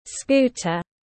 Scooter /ˈskuː.tər/